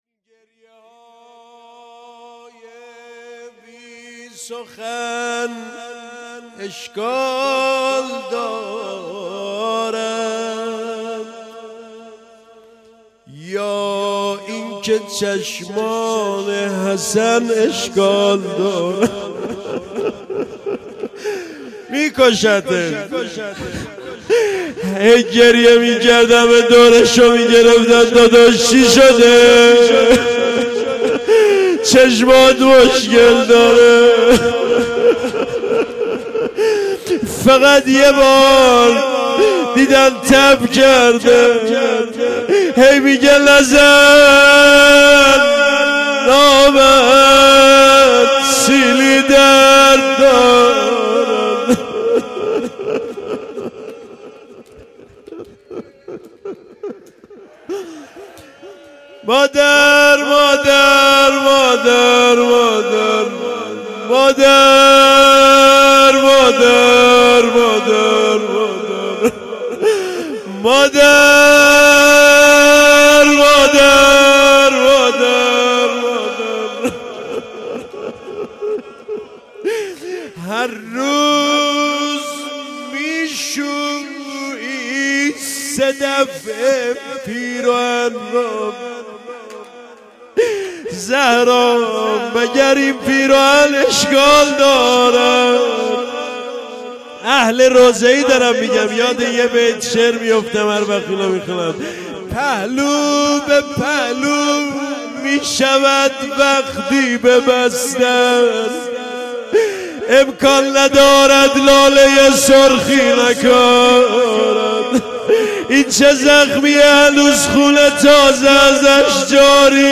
شب دوم فاطمیه مداحی